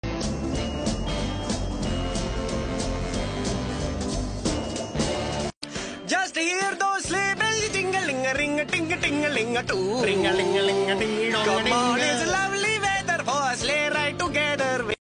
indian chrismas music Meme Sound Effect
indian chrismas music.mp3